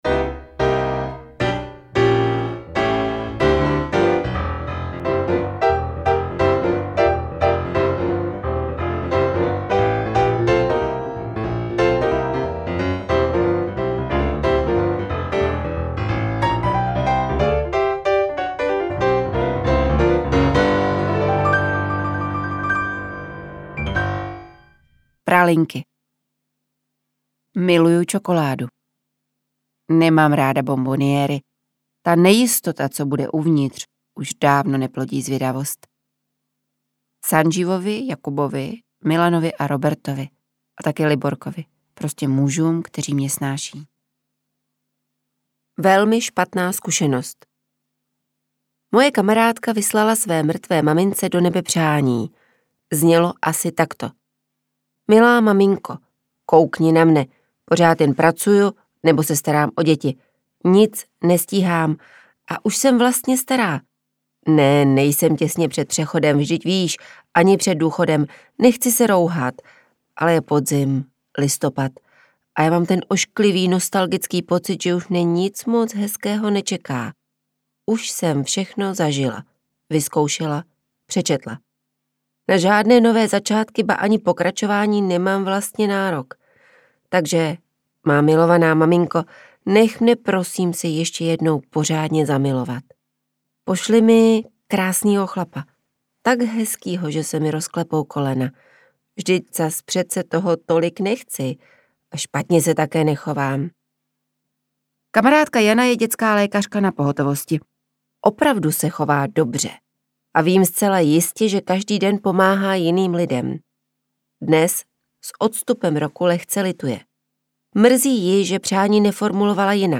Interpret:  Barbara Nesvadbová